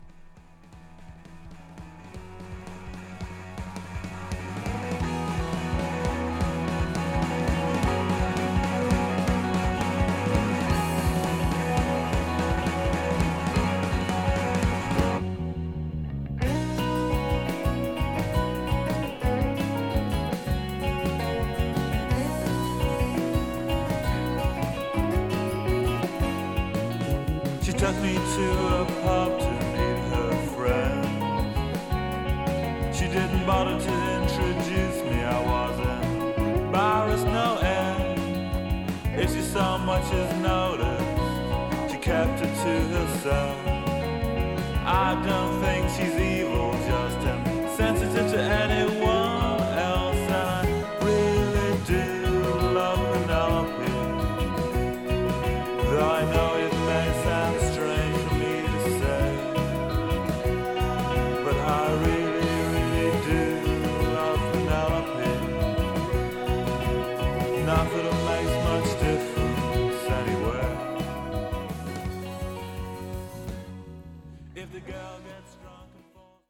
from Dublin ときめくギターのキラメキが疾走するクラブヒットなタイトル曲。
めくるめくギターのリフがカッコいいジャングリーナンバー